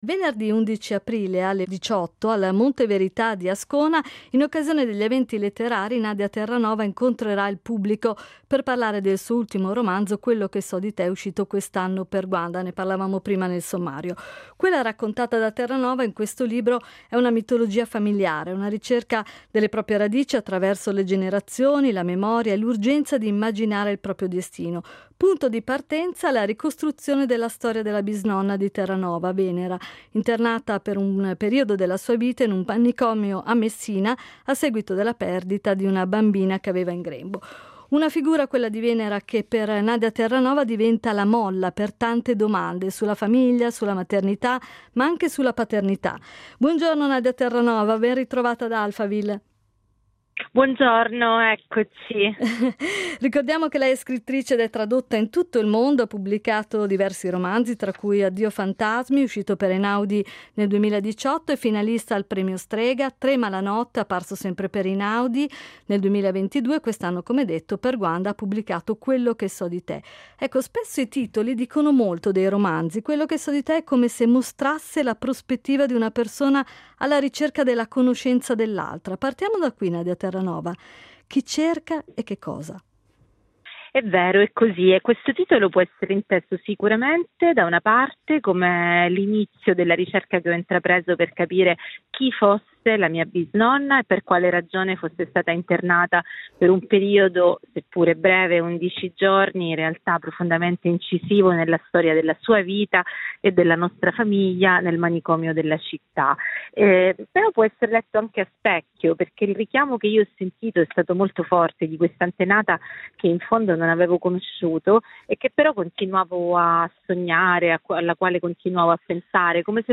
Noi l’abbiamo intervistata sui temi della memoria, della maternità e della ricerca delle proprie radici, su cui si sofferma il suo ultimo romanzo.